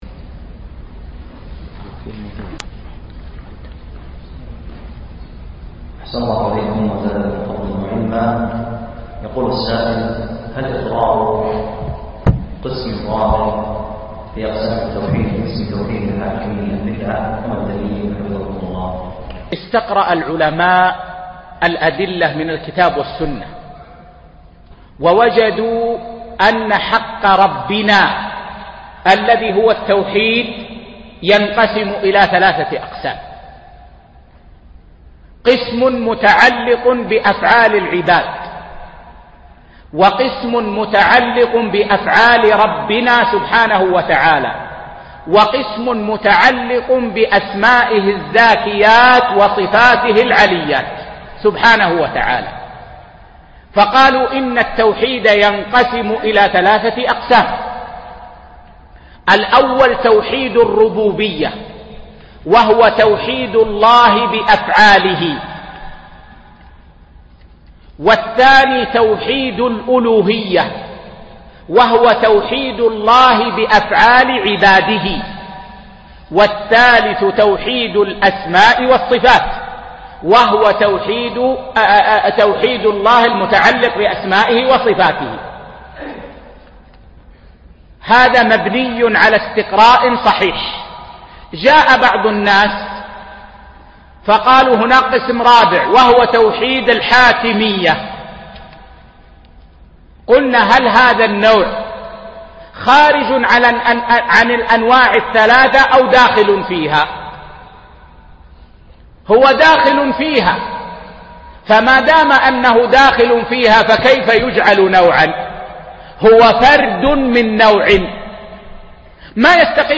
يوم السبت 19 شعبان 1436 الموافق 6 6 2015 في مسجد الفارس بدولة الكويت